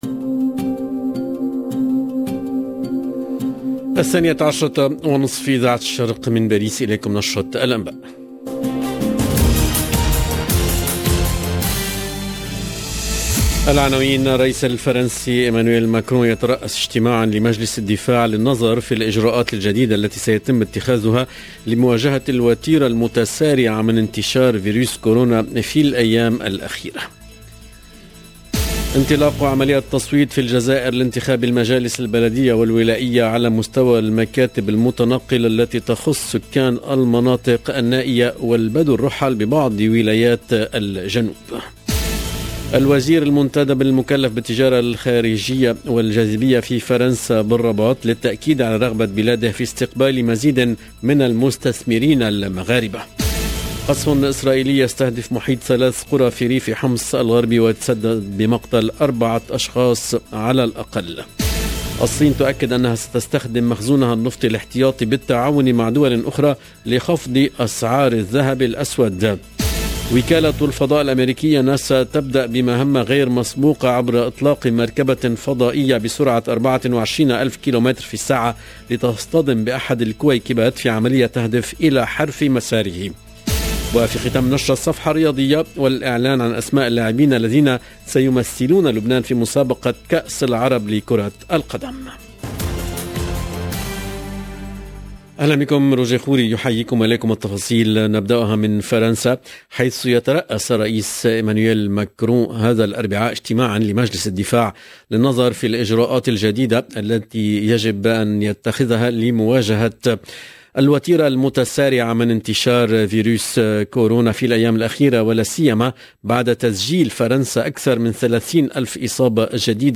LE JOURNAL DE MIDI 30 EN LANGUE ARABE DU 24/11/21